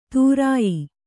♪ tūrāyi